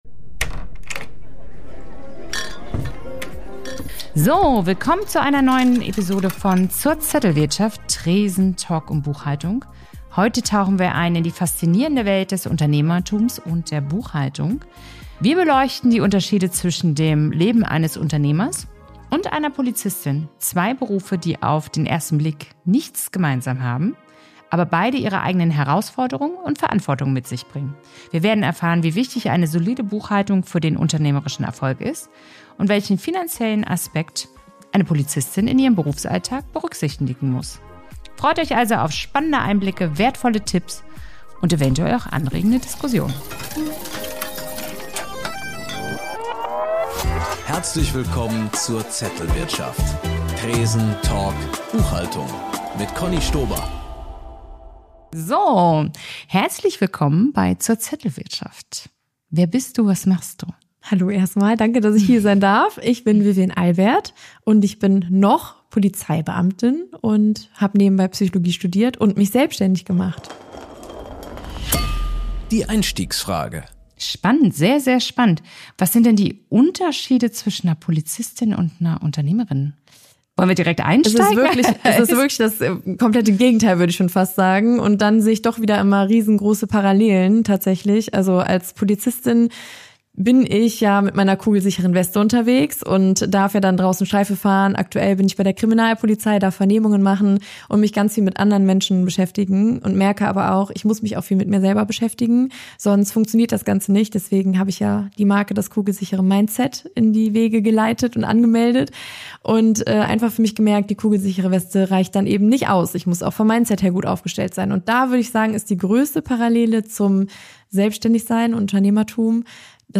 Ein Gespräch für alle, die Buchhaltung nicht nur verstehen, sondern als Werkzeug für unternehmerisches Wachstum nutzen wollen.